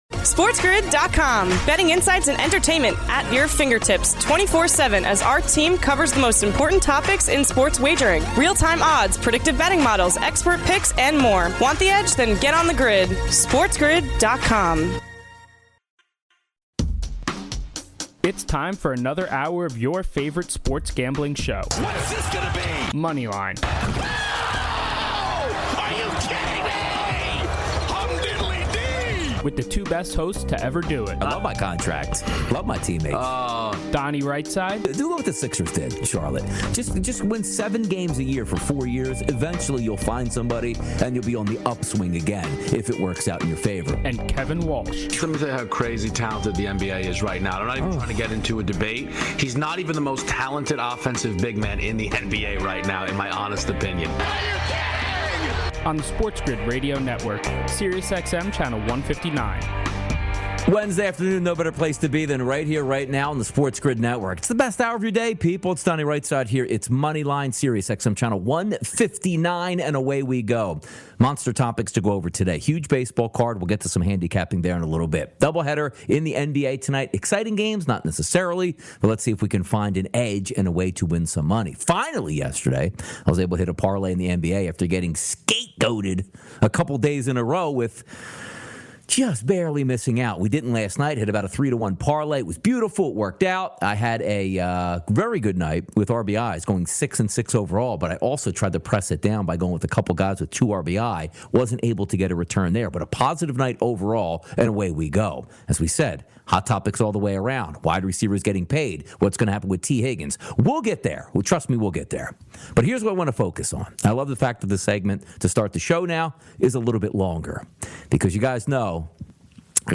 All this, NBA tonight, your calls, and more!